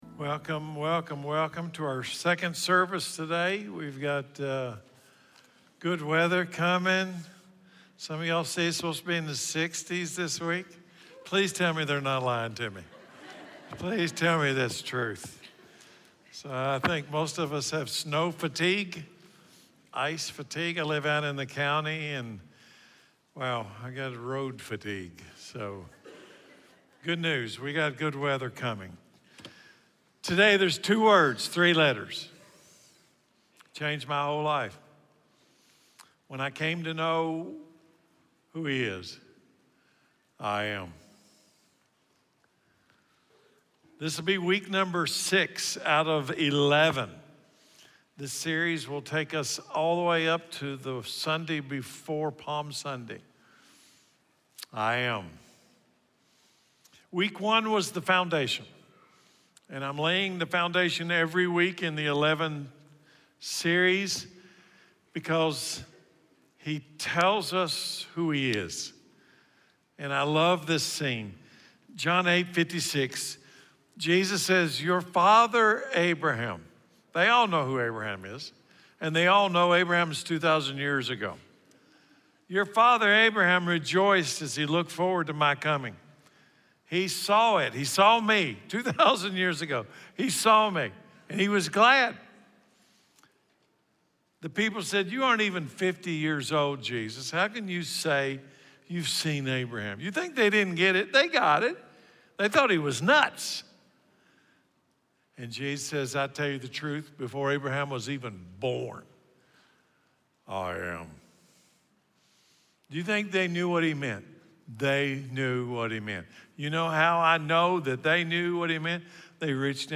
Jesus Is the Resurrection and the Life | John 11 Sermon